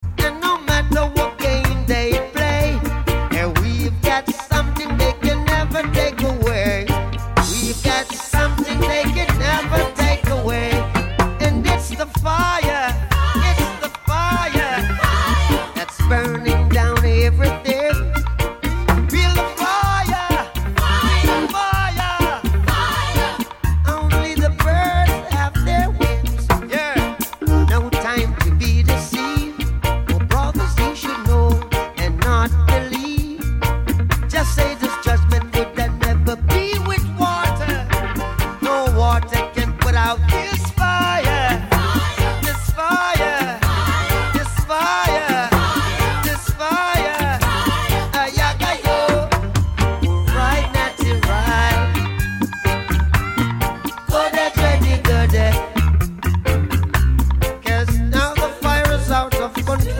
reggae
🛻💫 With hypnotic basslines, militant drums
prophetic voice